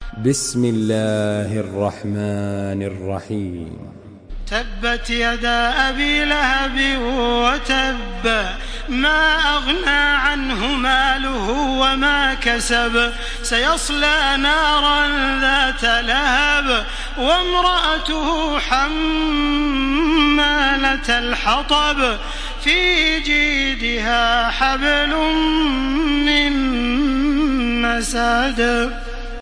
Surah Al-Masad MP3 in the Voice of Makkah Taraweeh 1434 in Hafs Narration
Murattal Hafs An Asim